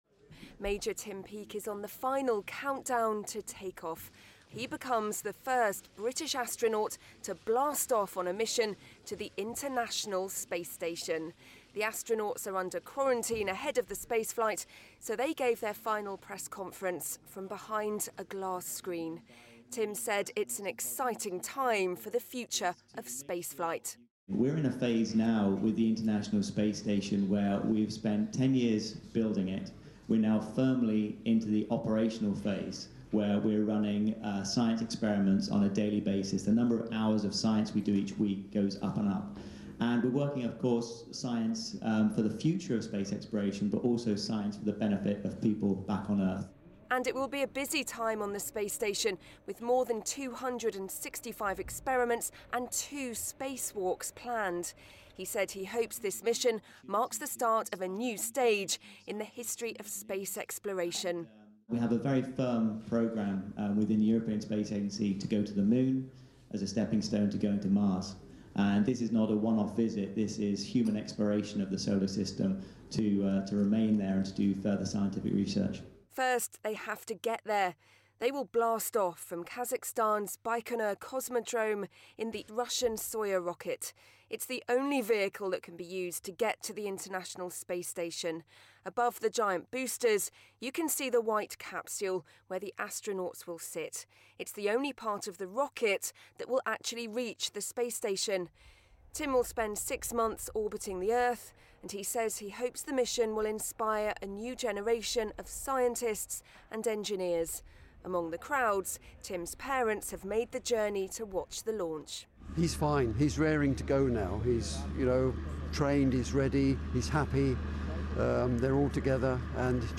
Maj Tim Preceding Day's Launch Press Conference